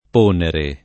ponere [ p 1 nere ]